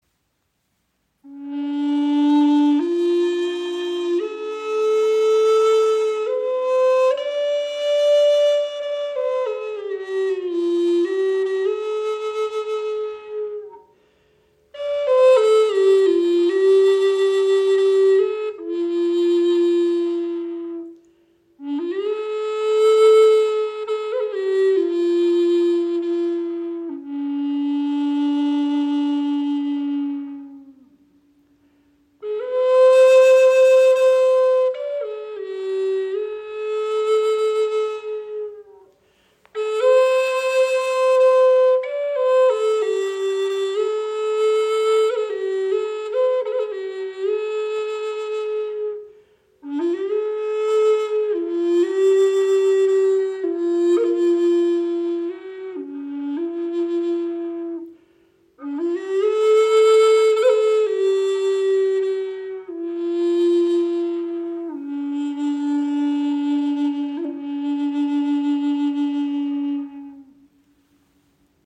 Gebetsflöte in tiefem C - 432 Hz
• Icon 64 cm lang, 6 Grifflöcher